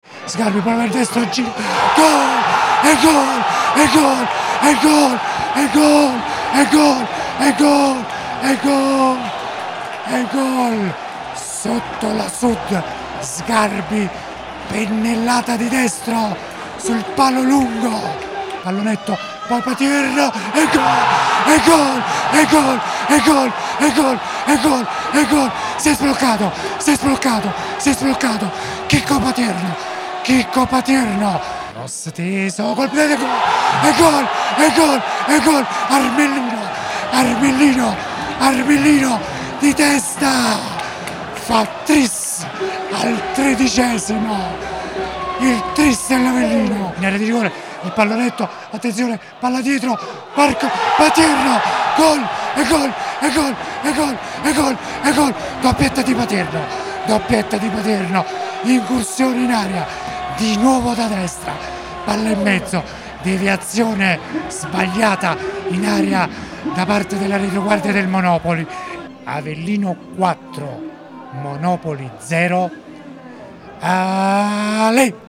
Gol Avellino-Monopoli 4-0 con la Radiocronaca
GOL AVELLINO-MONOPOLI 4-0: L’ESULTANZA
GOL-AVELLINO-MONOPOLI.mp3